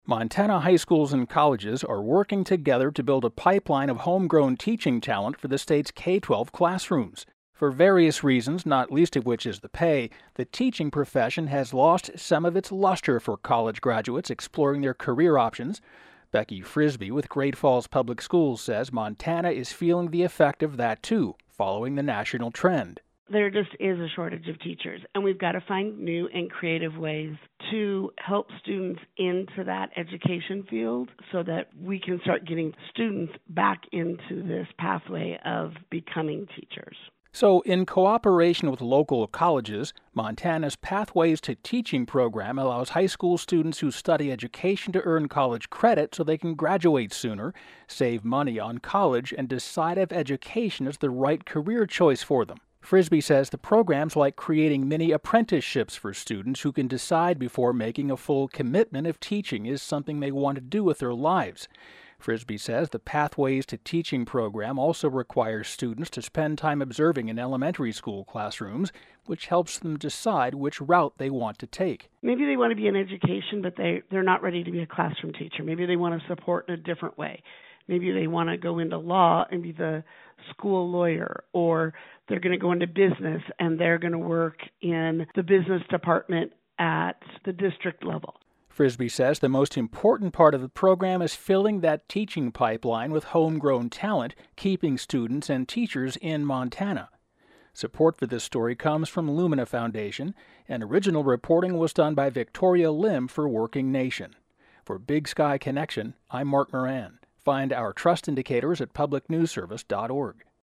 Broadcast version